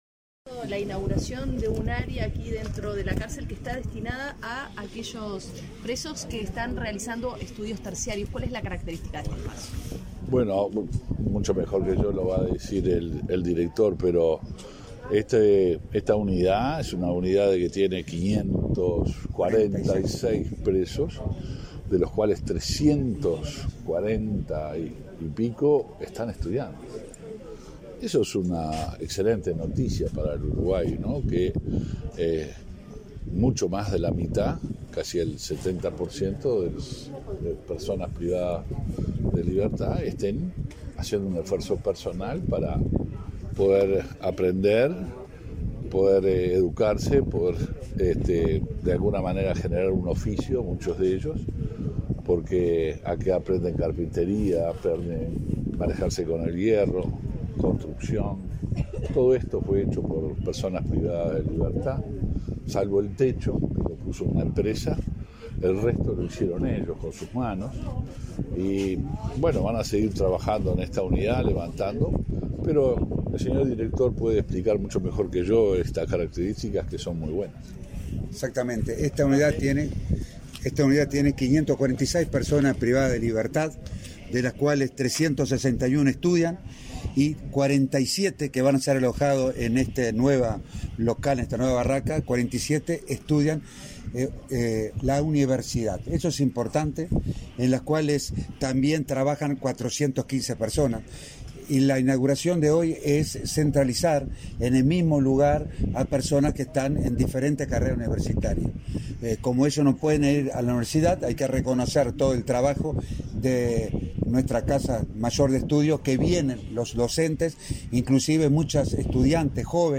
Declaraciones a la prensa del ministro de Interior, Luis Alberto Heber, y del director del INR, Luis Mendoza
Declaraciones a la prensa del ministro de Interior, Luis Alberto Heber, y del director del INR, Luis Mendoza 09/06/2022 Compartir Facebook X Copiar enlace WhatsApp LinkedIn Tras la inauguración de pabellones para universitarios en la cárcel de Punta de Rieles, este 9 de junio, el ministro del Interior, Luis Alberto Heber, y el director del Instituto Nacional de Rehabilitación (INR), Luis Mendoza, efectuaron declaraciones a la prensa.